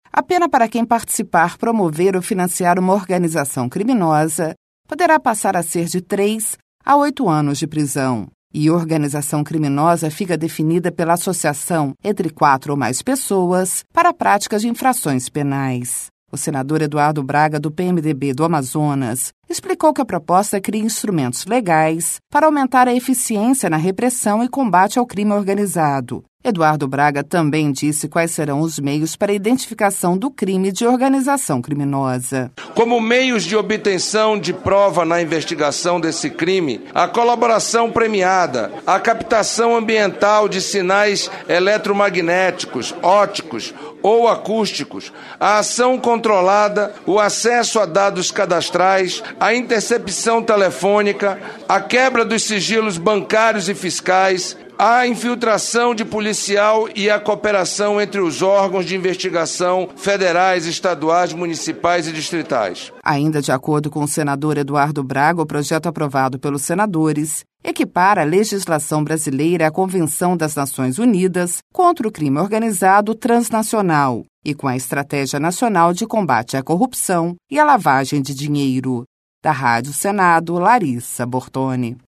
O senador Eduardo Braga, do PMDB do Amazonas, explicou que a proposta cria instrumentos legais para aumentar a eficiência na repressão e combate ao crime organizado.